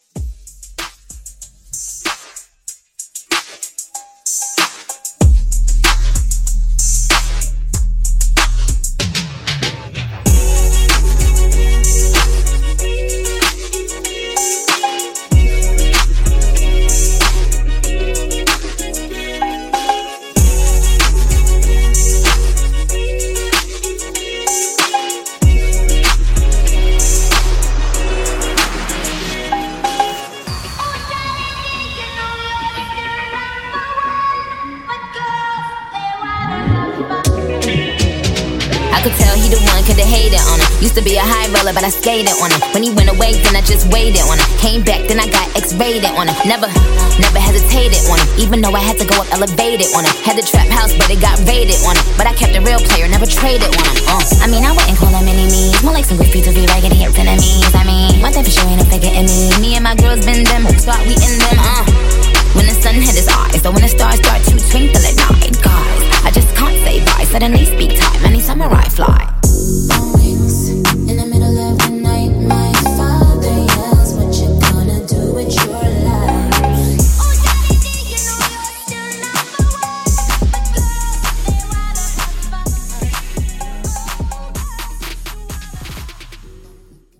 Genre: HIPHOP
Dirty BPM: 84 Time